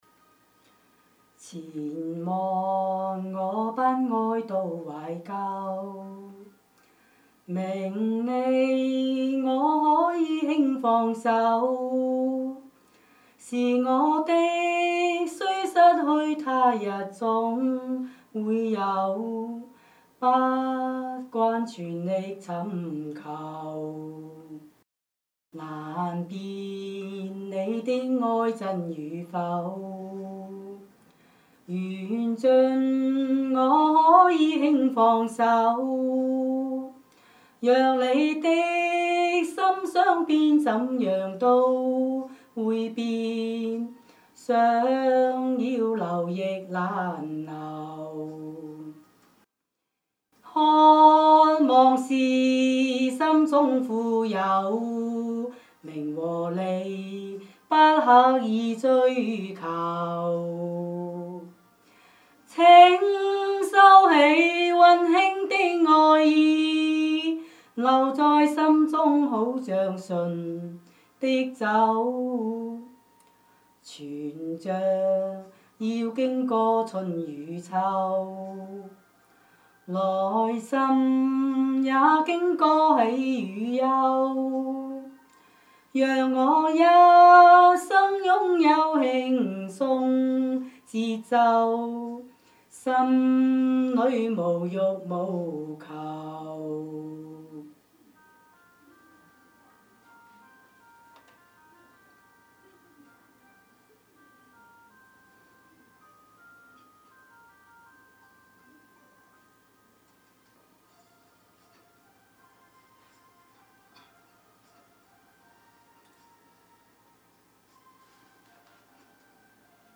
清唱